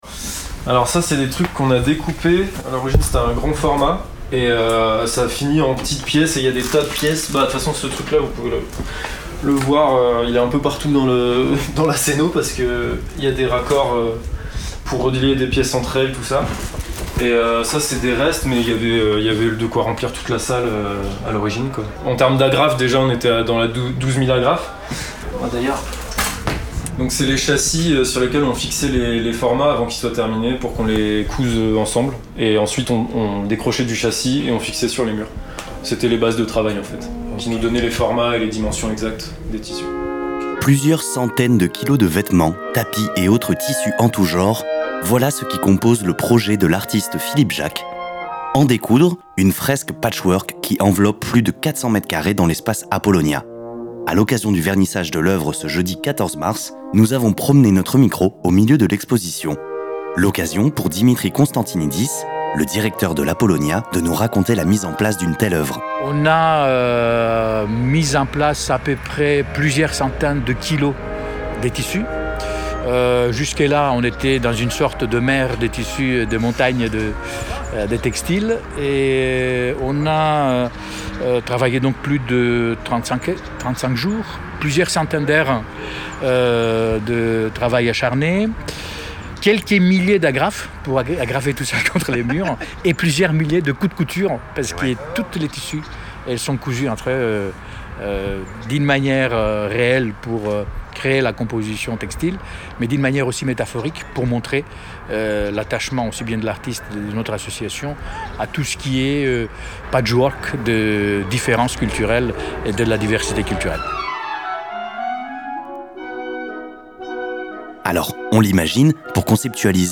Retrouvez le reportage d’Accent 4, enregistré ce jeudi 14 mars, à l’occasion du vernissage de l’exposition En découdre!